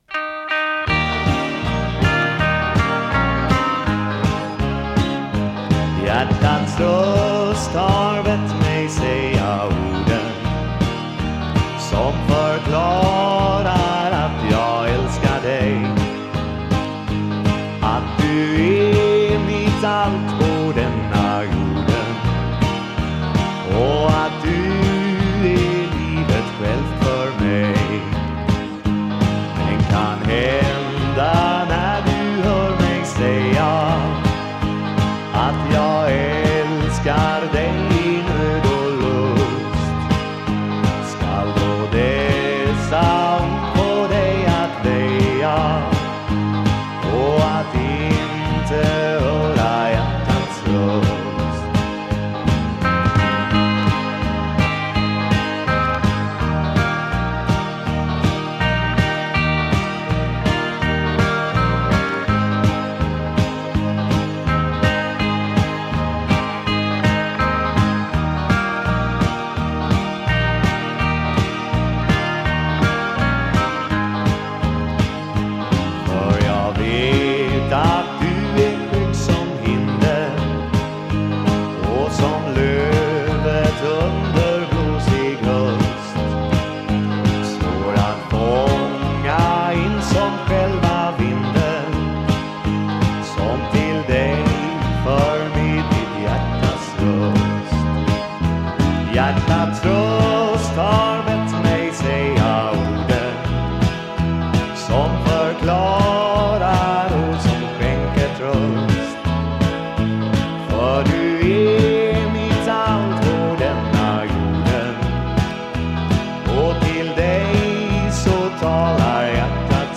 Vocals, Piano, Accordion
Bass
Vocals, Drums, Percussion
Vocals, Guitars
Sax